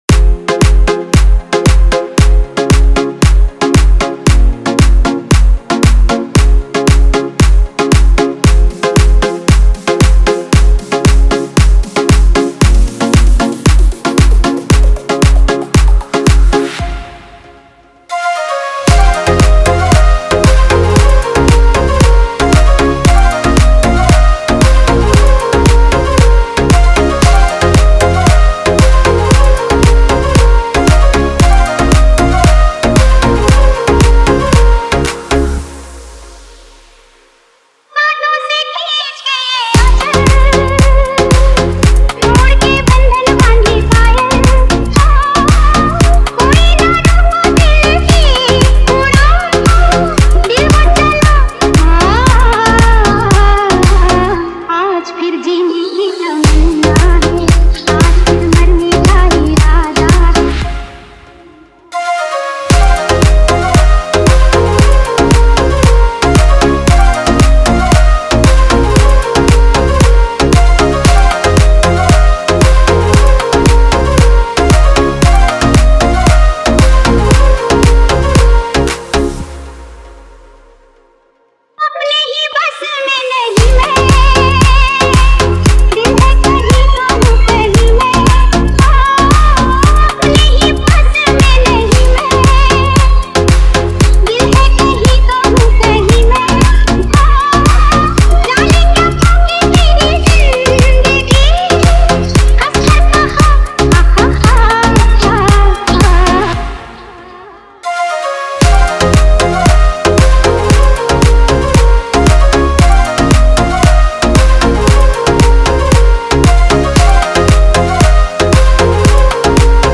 Releted Files Of DJ Remix